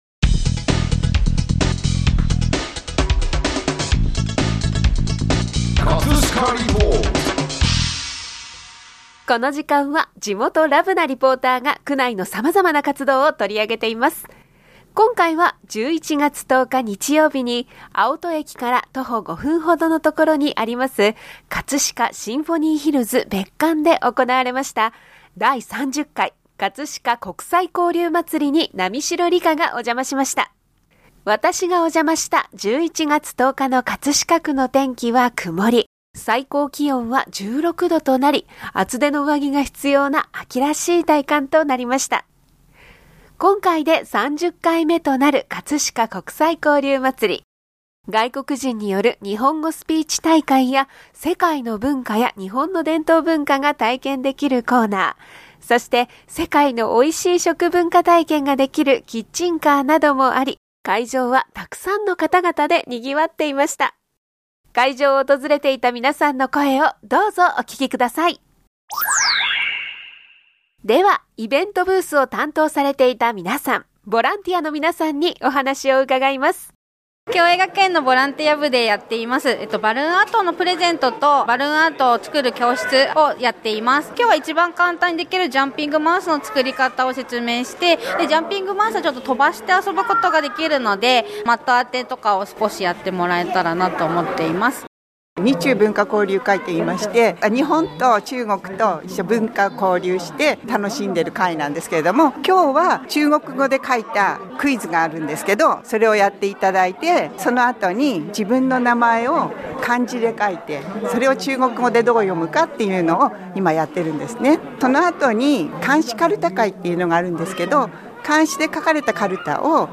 【葛飾リポート】 葛飾リポートでは、区内の様々な活動を取り上げています。
外国人による日本語スピーチ大会や世界の文化や日本の伝統文化が体験できるコーナー、そして世界の美味しい食文化体験ができるキッチンカーなどもあり、会場は沢山の皆さんでにぎわっていました。 会場を訪れていた皆さんの声をどうぞお聴き下さい！